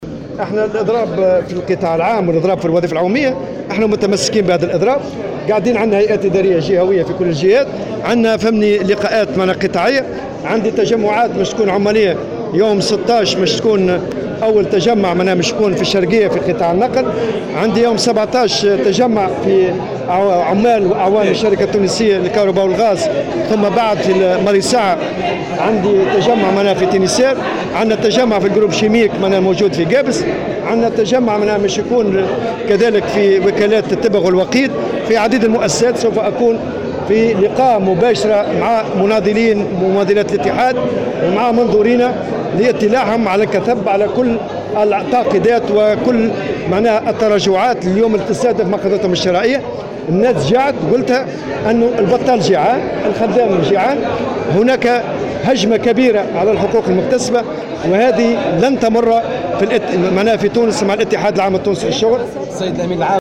على هامش مؤتمر الجامعة العامة للمتقاعدين في الحمامات